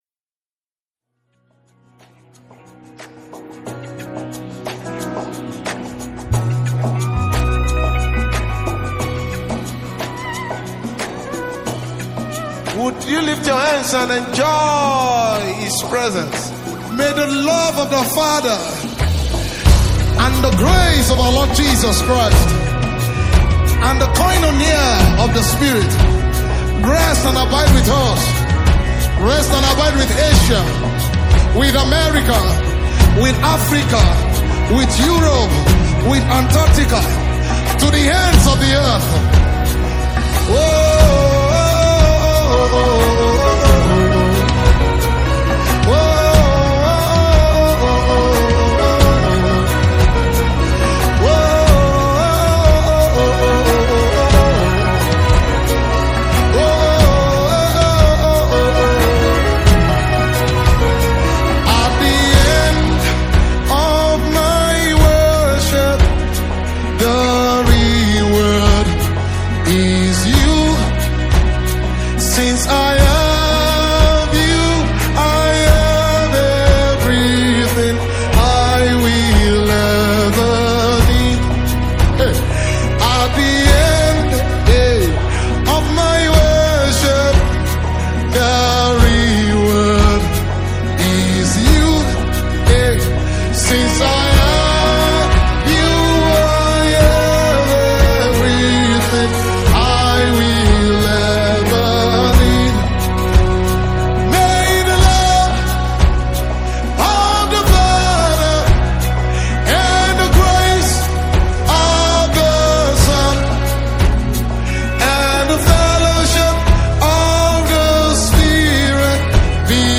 Nigerian Gospel Music